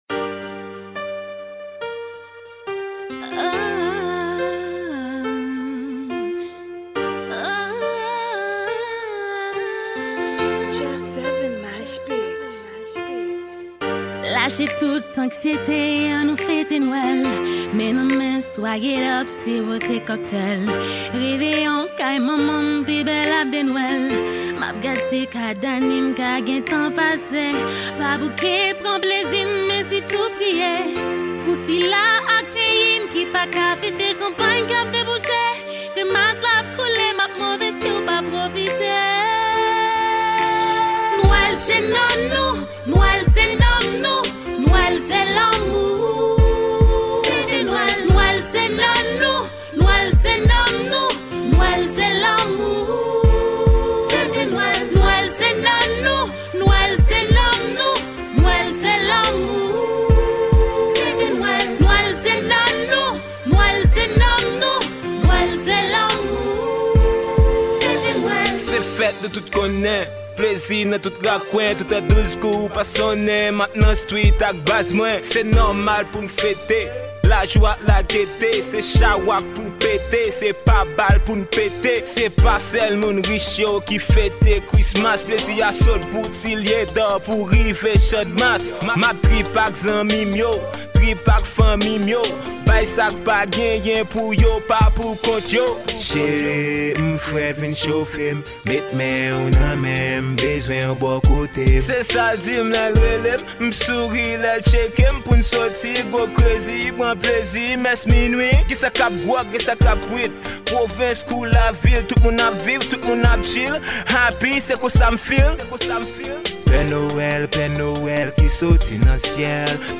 Genre: Rap RNB.